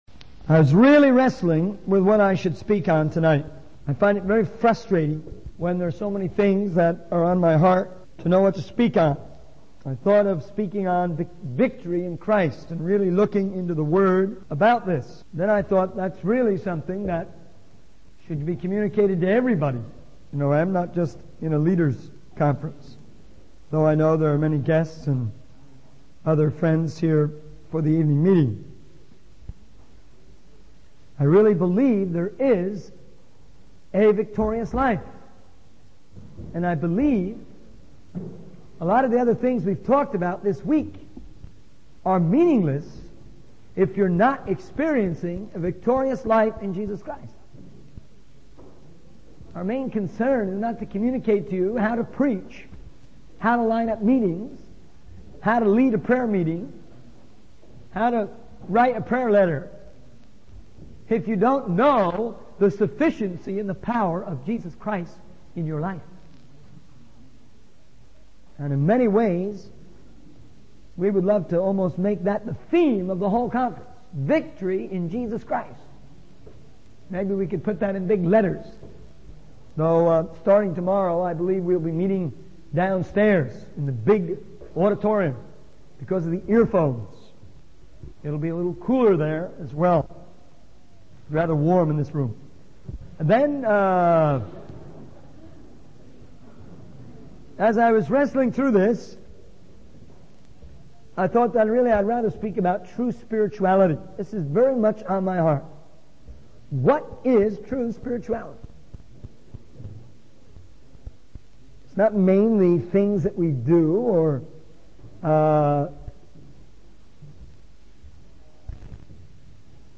In this sermon, the speaker discusses the strong points of the OM movement and emphasizes the importance of teamwork. He highlights the uniqueness of OM in practicing and promoting teamwork, which involves living and working together in the same house or on the same team.